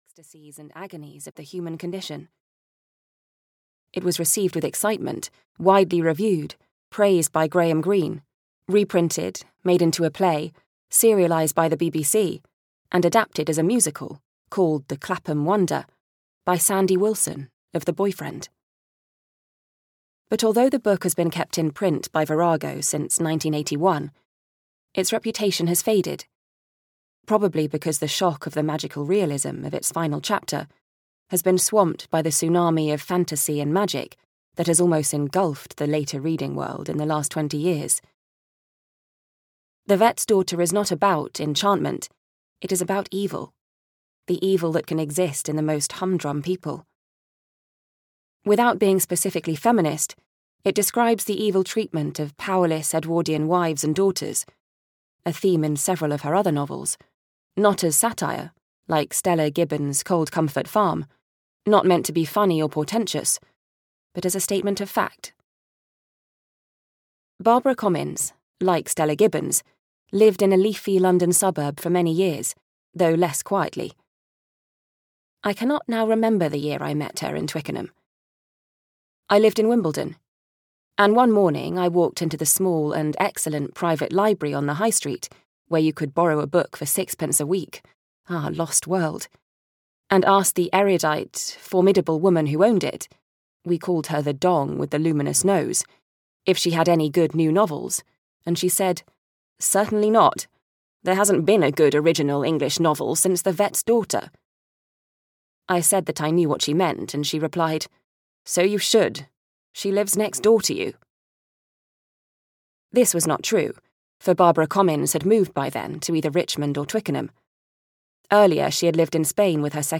The Vet's Daughter (EN) audiokniha
Ukázka z knihy